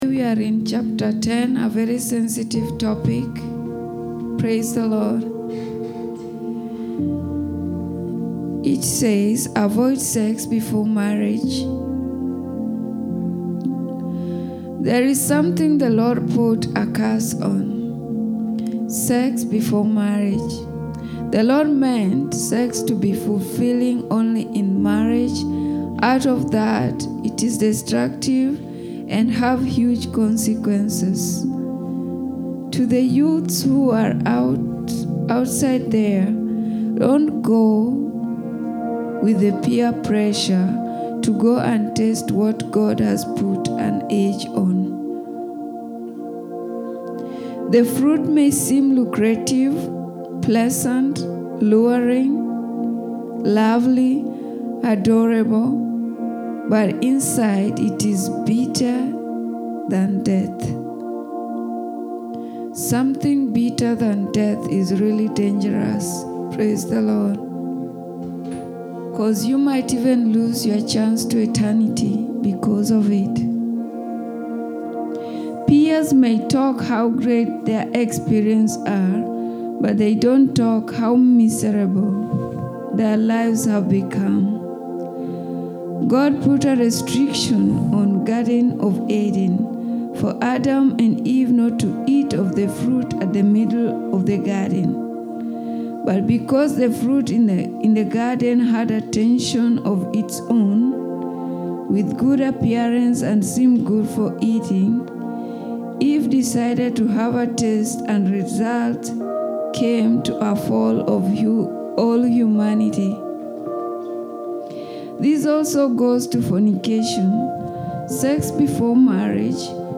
avoid-sex-before-marriage-book-review.mp3